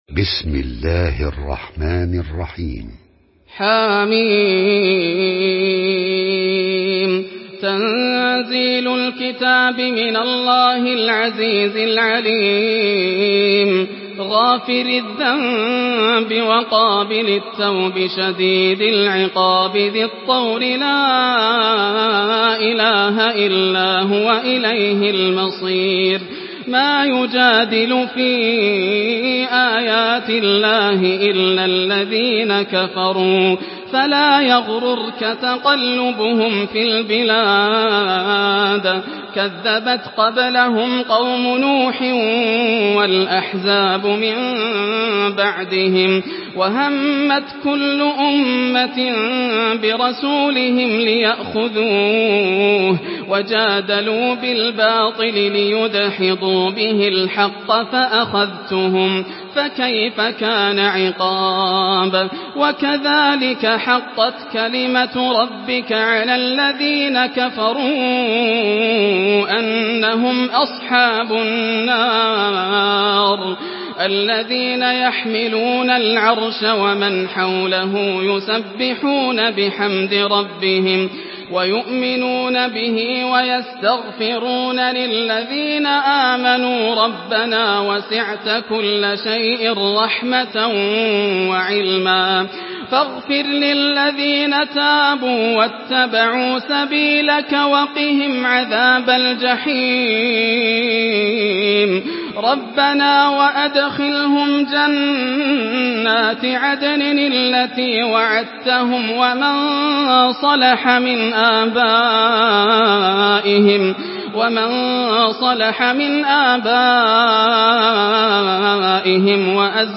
Surah গাফের MP3 by Yasser Al Dosari in Hafs An Asim narration.
Murattal Hafs An Asim